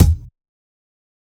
percussion_0032.wav